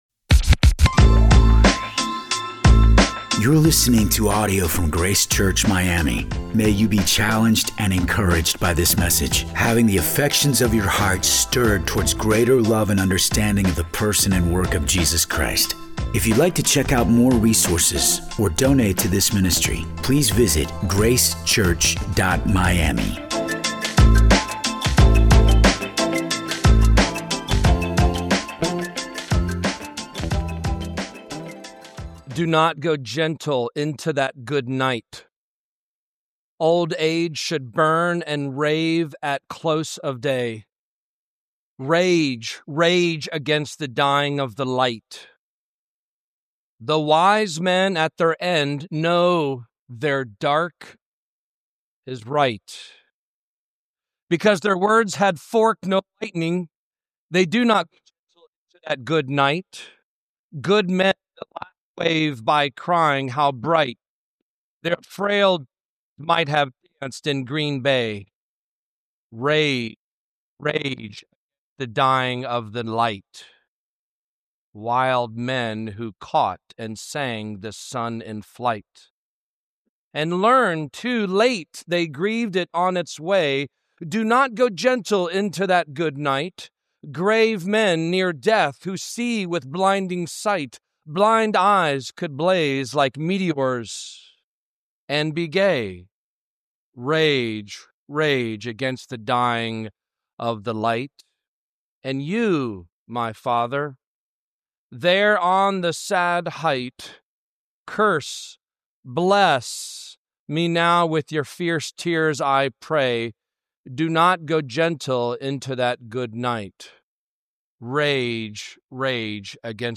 Grace Church Miami - Sermons